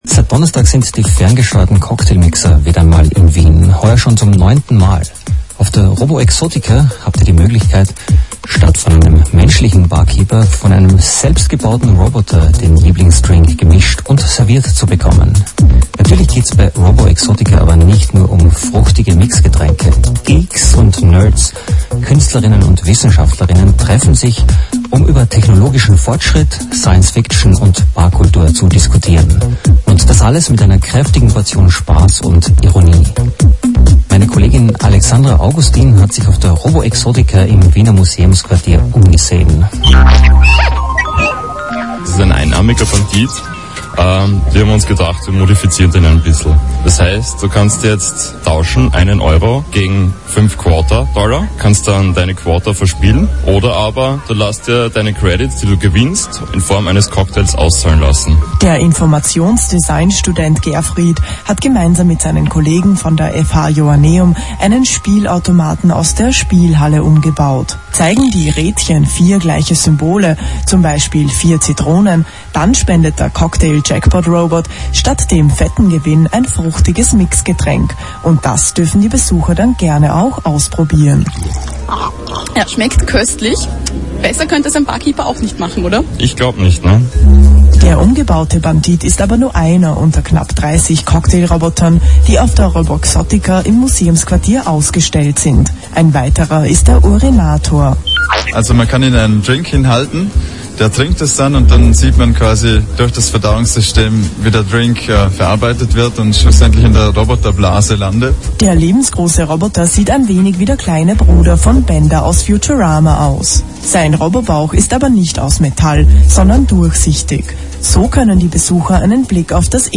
FM4 radio report about Roboexotica
FM4 radio report about Roboexotica, partially in English language.